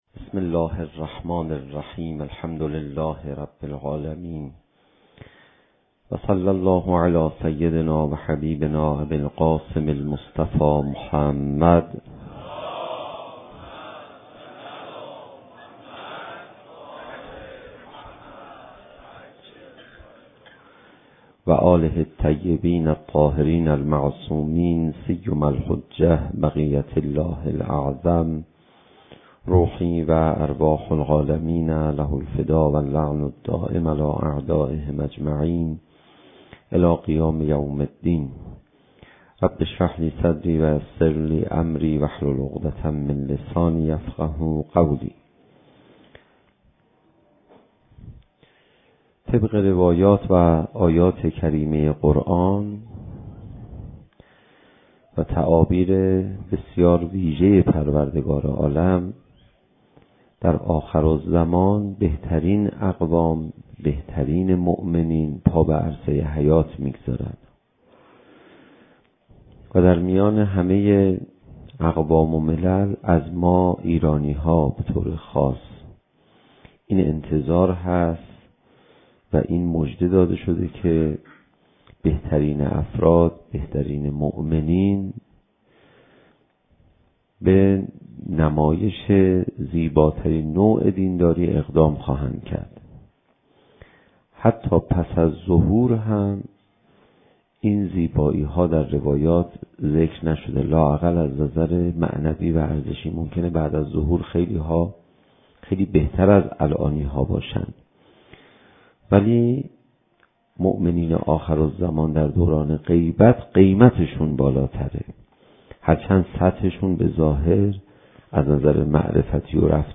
زمان: 56:48 | حجم: 13.3 MB | تاریخ: 1393 | مکان: حسینیة آیت الله حق شناس